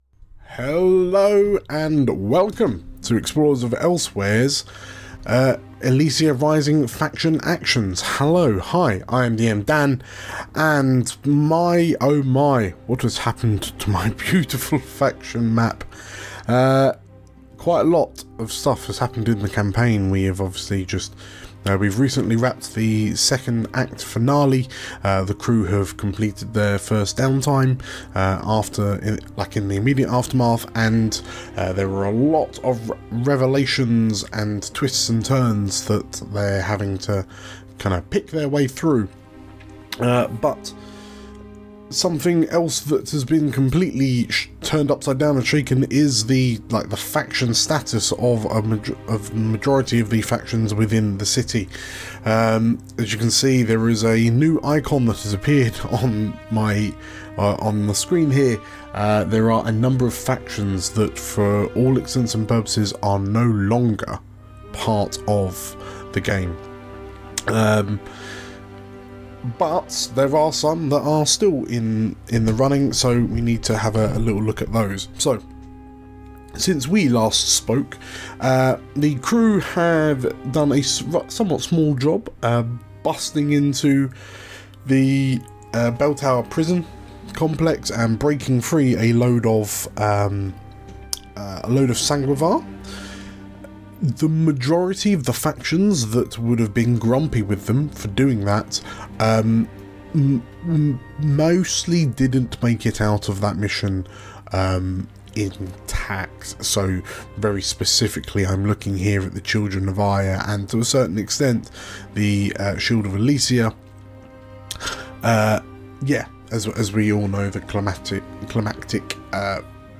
Faction Actions! - Elysia Rising Ep16 - Blades in the Dark Actual Play